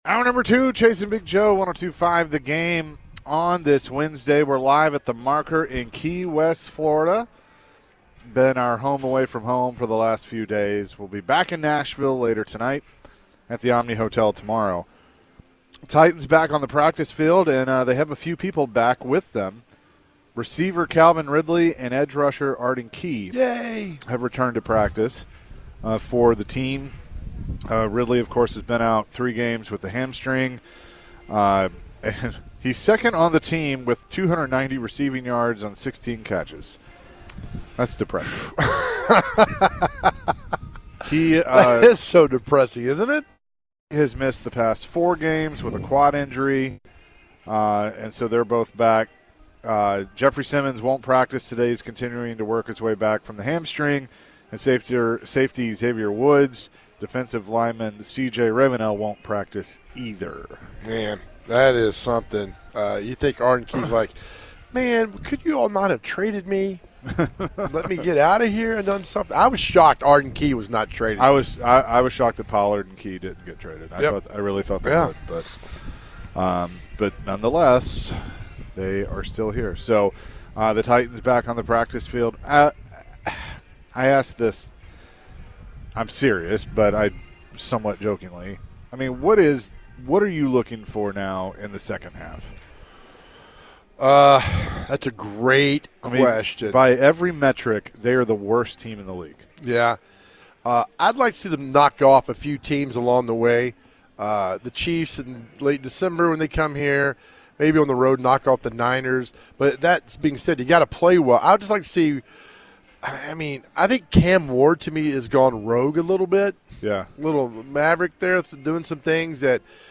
The guys have callers on the show to hear their thoughts on the Titans. The guys consider what position the Titans should draft first and have viewers weigh in.